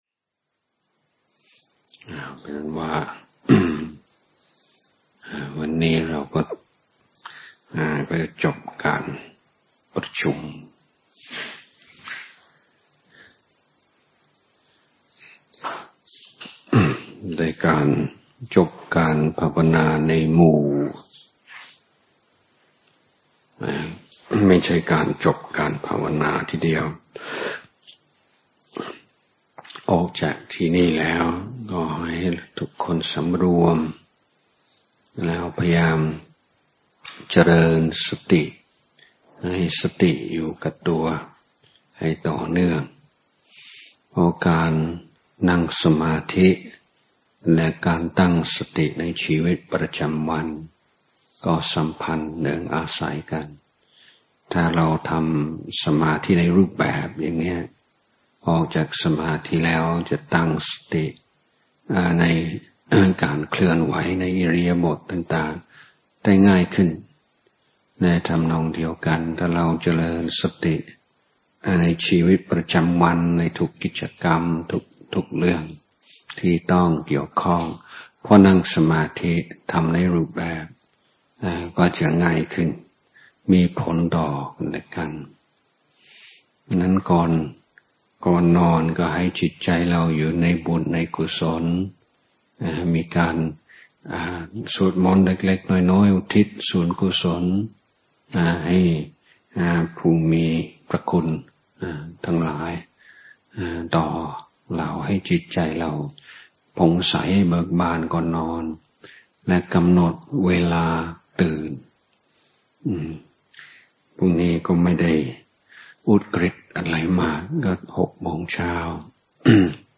พระพรหมพัชรญาณมุนี (ฌอน ชยสาโร) - โอวาทก่อนนอน